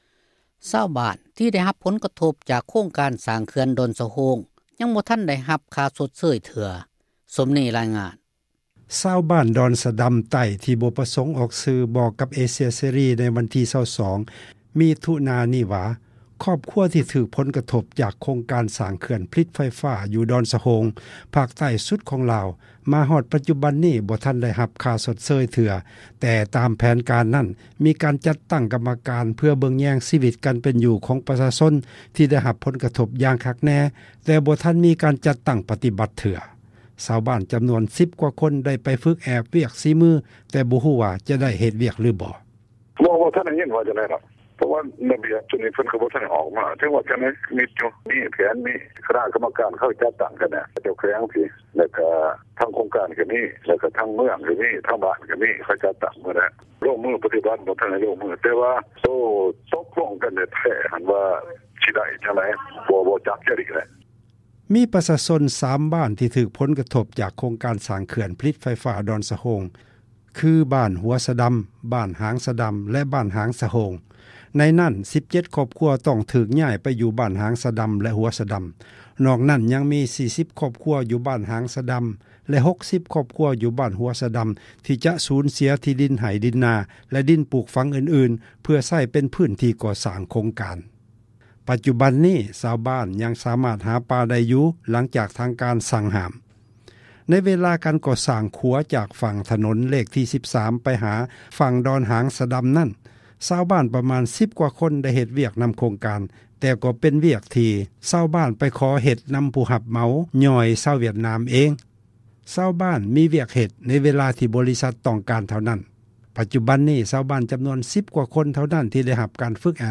ຊາວບ້ານ ດອນສະດໍາ ໃຕ້ ທີ່ ບໍ່ປະສົງ ອອກຊື່ ບອກກັບ ເອເຊັຽ ເສຣີ ໃນ ວັນທີ່ 22 ມິຖຸນາ ນີ້ວ່າ, ຄອບຄົວ ທີ່ ຖືກ ຜົນ ກະທົບ ຈາກ ໂຄງການ ສ້າງ ເຂື່ອນ ດອນສະໂຮງ ພາກໃຕ້ ສຸດ ຂອງລາວ, ມາຮອດ ປັດຈຸບັນ ນີ້ ກໍບໍ່ທັນ ໄດ້ຮັບ ຄ່າ ຊົດເຊີຍ ເທື່ອ, ແຕ່ ຕາມແຜນ ນັ້ນ ມີການ ຈັດຕັ້ງ ກັມມະການ ເພື່ອ ເບິ່ງແຍງ ຊີວິດ ການເປັນຢູ່ ຂອງ ປະຊາຊົນ ທີ່ ໄດ້ ຮັບ ຜົນກະທົບ ຢ່າງ ຄັກແນ່, ແຕ່ ບໍ່ທັນມີ ການ ຈັດຕັ້ງ ປະຕິບັດ ເທື່ອ. ຊາວບ້ານ ກວ່າ 10 ຄົນ ໄດ້ໄປ ຝຶກແອບ ວຽກ ສີມື, ແຕ່ ບໍ່ຮູ້ວ່າ ຈະໄດ້ ເຮັດວຽກ ຫລືບໍ່: